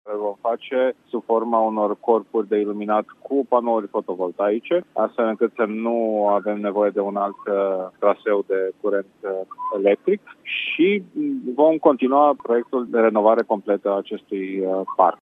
Viceprimarul Timişoarei, Dan Diaconu, spune că în curând parcul va fi şi iluminat, pentru prima dată în istoria sa.